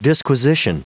Prononciation du mot disquisition en anglais (fichier audio)
Prononciation du mot : disquisition